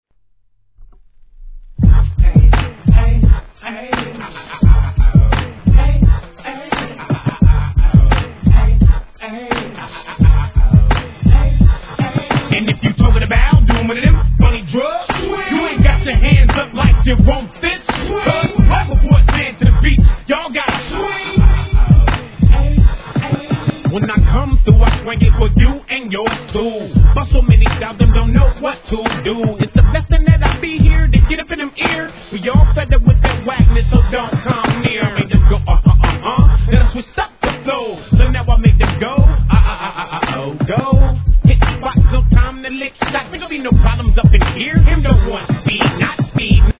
G-RAP/WEST COAST/SOUTH
エフェクト・ベースにハイハットのFUNKY BEATにドンピシャリ!!